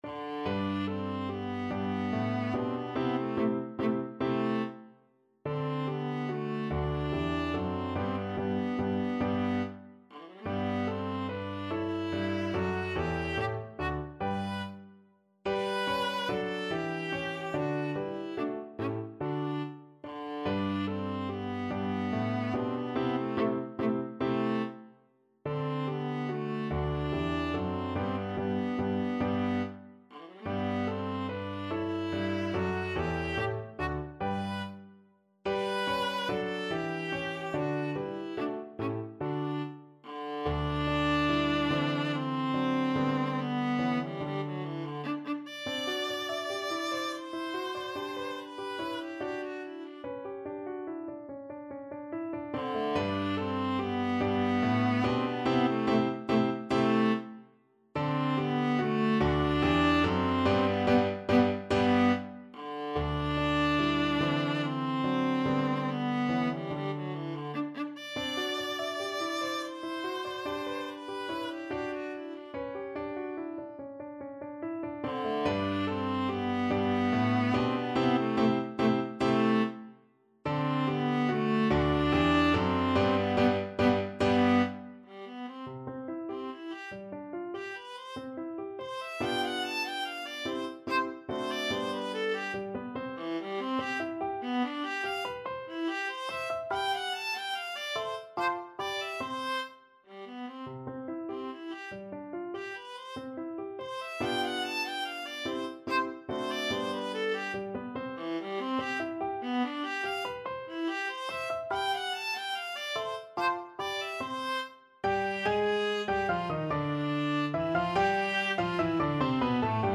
Viola
G major (Sounding Pitch) (View more G major Music for Viola )
Moderato =c.144
3/4 (View more 3/4 Music)
Classical (View more Classical Viola Music)